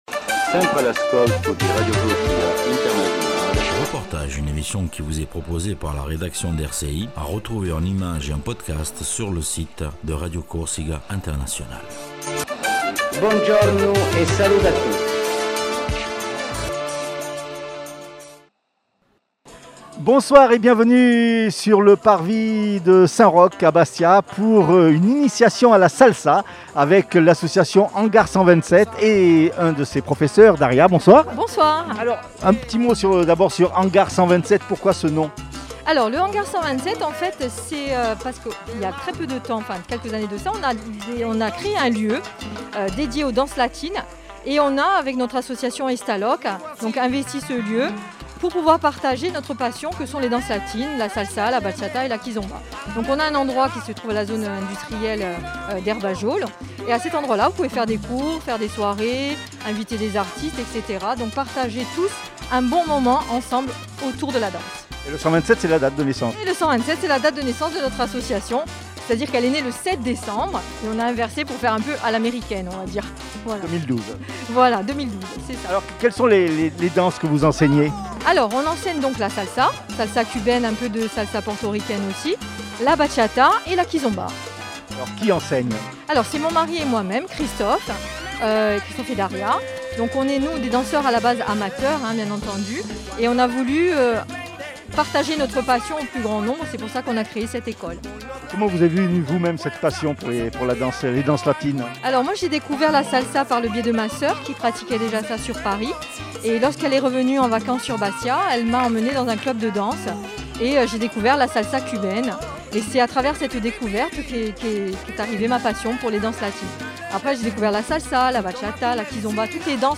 reportage hangar127